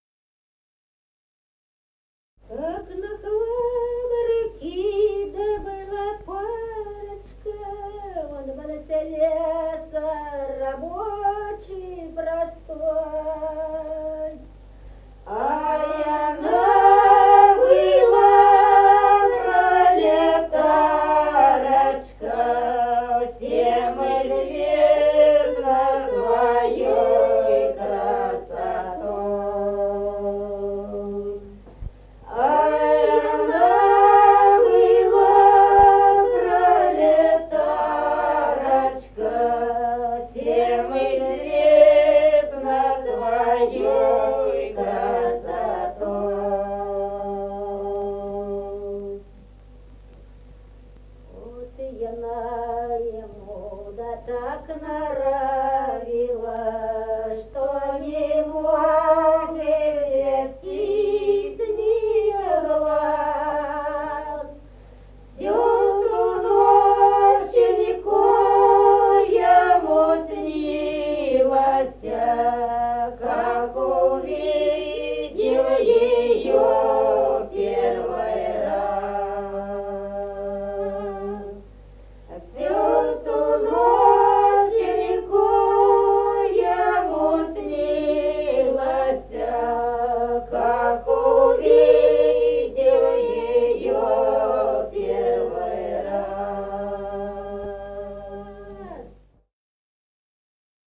Песни села Остроглядово Как на фабрике была парочка.